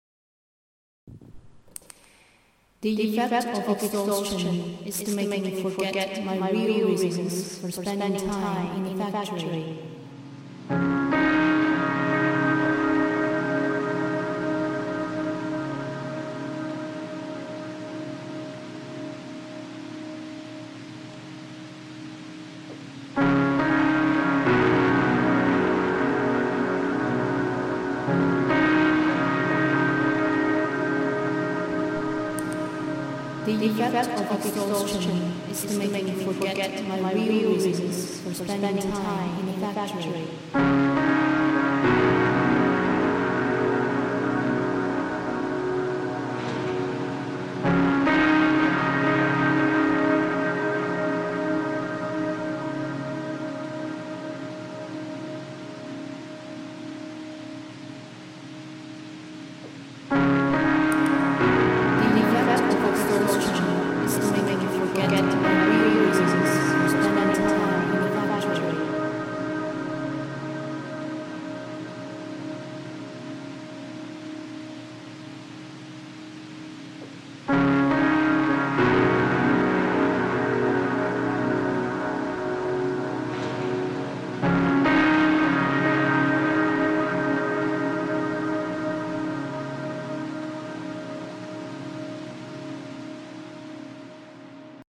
Here is the second extract from the Factory Journal project- A dialogue in sound and image with Simone Weil’s seminal Factory Journal.